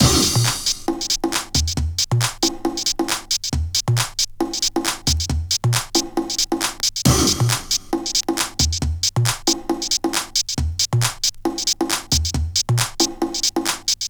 • baby percussion loop - tribal voices - A#m - 136.wav
Vinyl sample.
baby_perc__-_A_sharp_m_-_136_QYW.wav